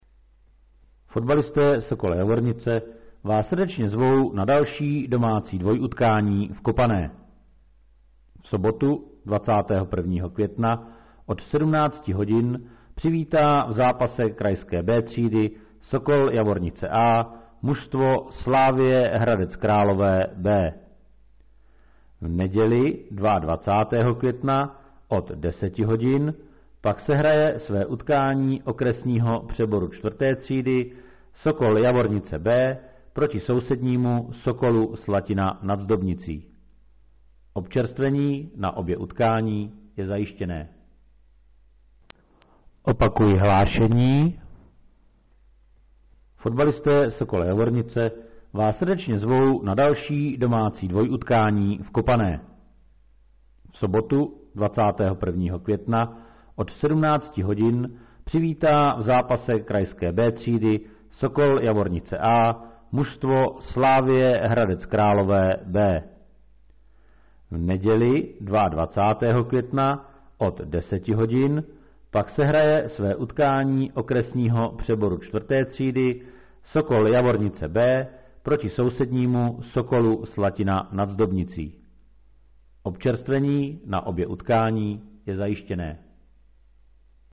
Obec Javornice - Hlášení obecního rozhlasu